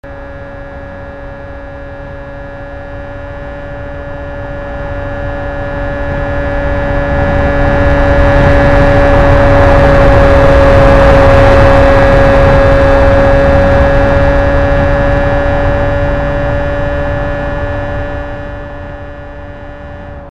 It was like this before the patch but increasing the engine sound boost has a linear effect on increasing 'the other' sounds too (wind/tyres)..
hear the mp3, what happens when i put the engine boost slider from full left to full right to full left. (warning some distortion..)